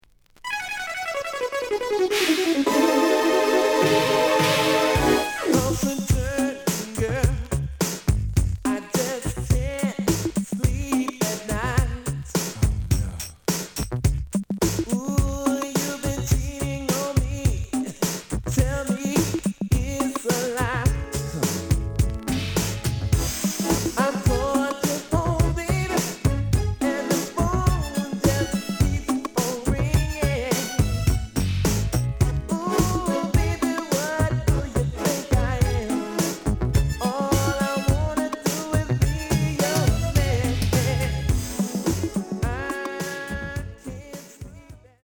●Genre: Hip Hop / R&B